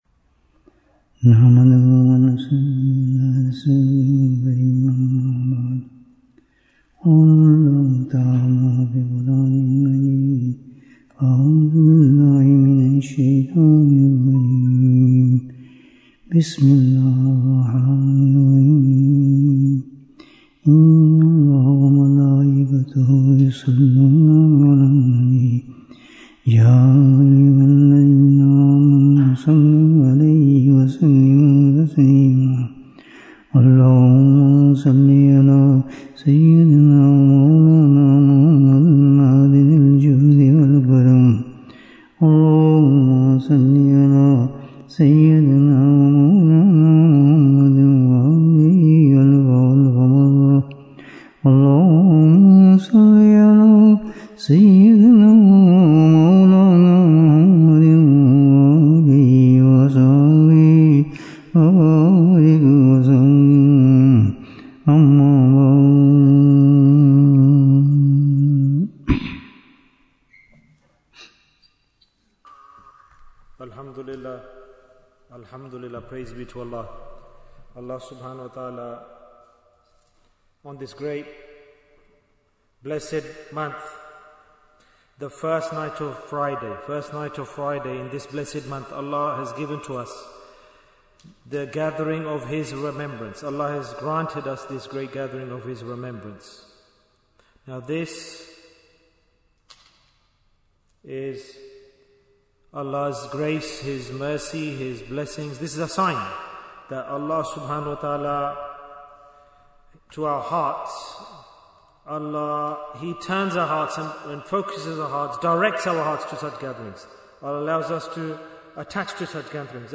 Bayan, 70 minutes28th August, 2025